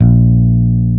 HARD FINGE01.wav